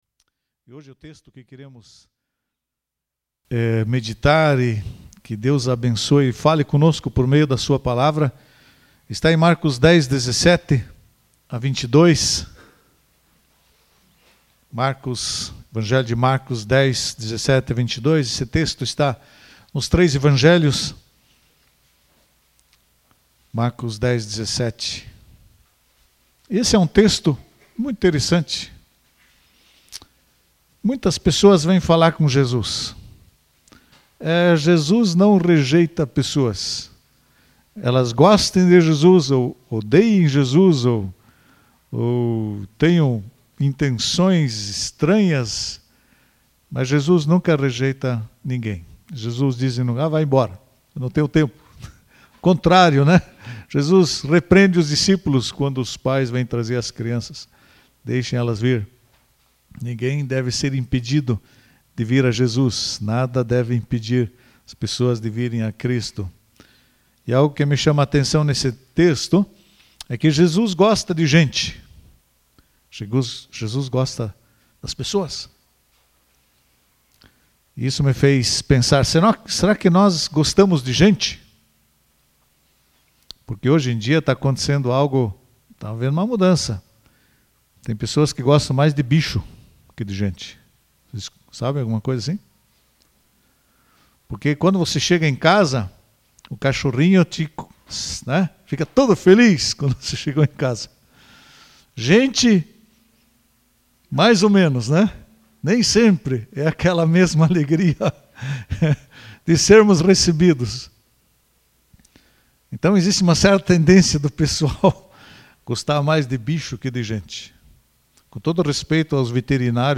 Palavra ministrada domingo